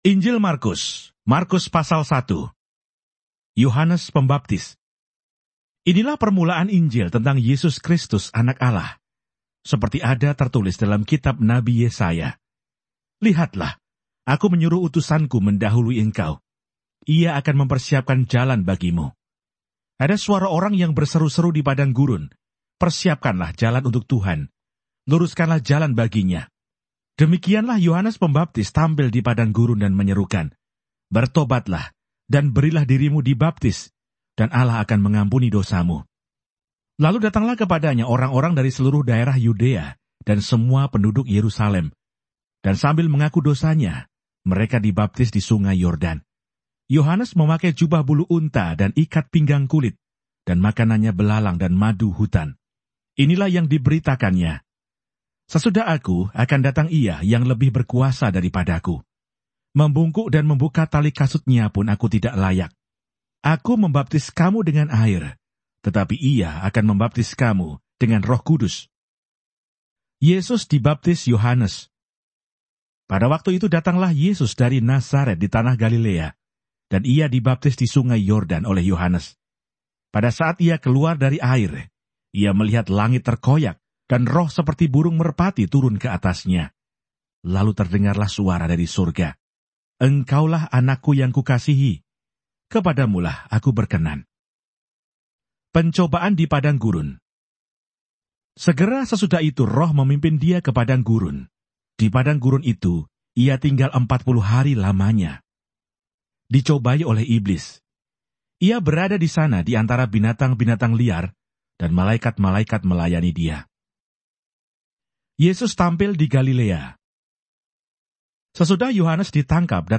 • Word for word narration
• Voice only Bible reading
bahasa-bible-6755-luke-1.mp3